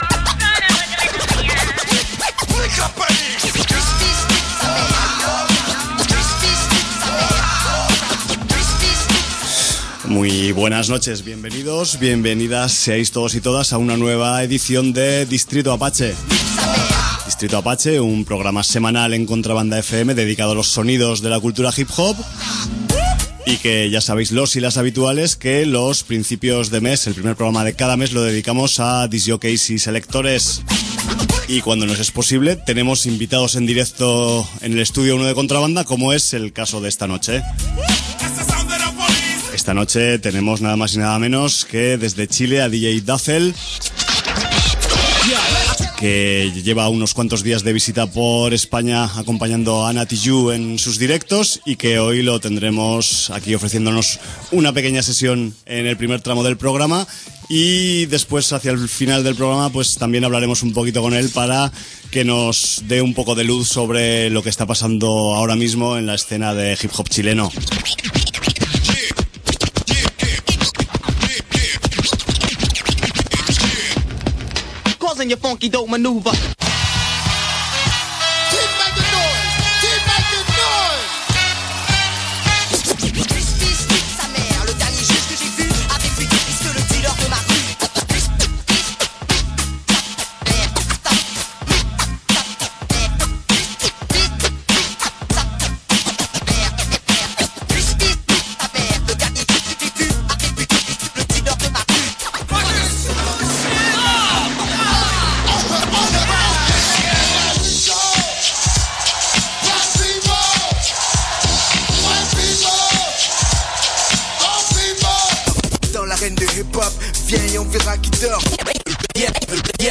y nos obsequia con una ilustrativa sesión dominada por beats de alto contenido vitamínico.